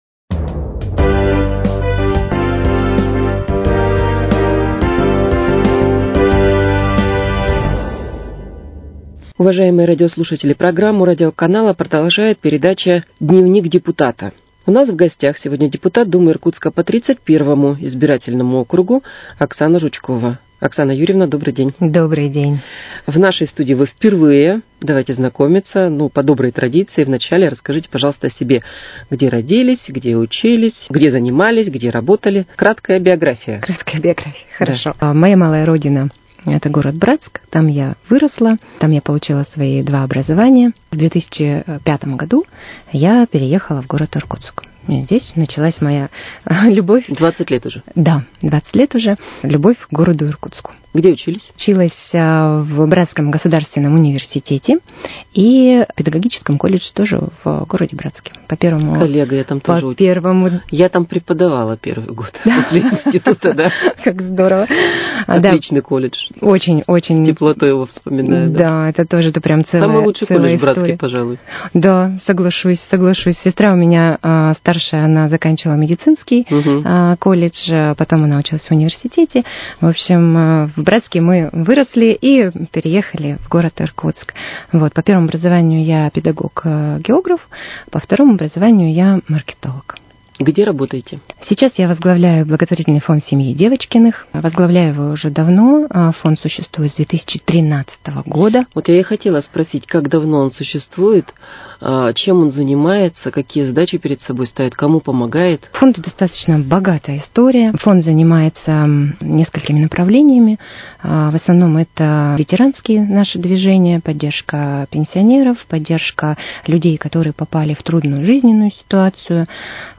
С депутатом Думы Иркутска по 31 избирательному округу Оксаной Жучковой беседует